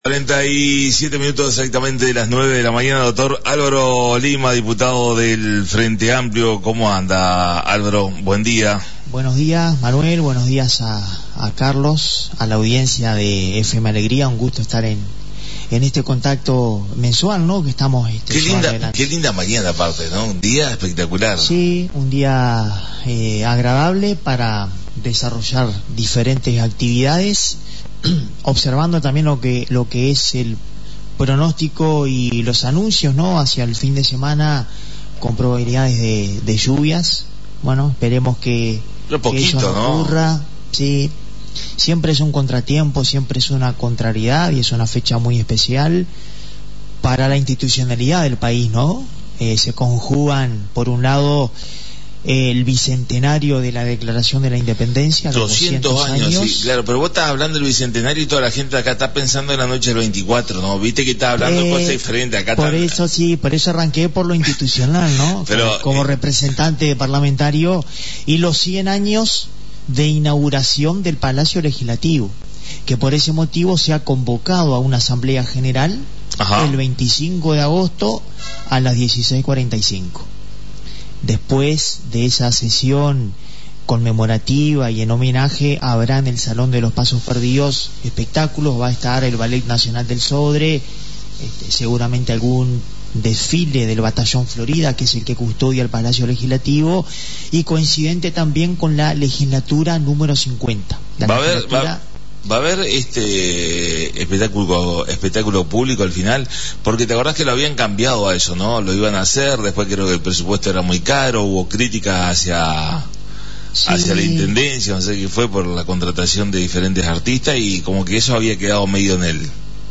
La visita a la Radio del Diputado por el Frente Amplio Dr Álvaro Lima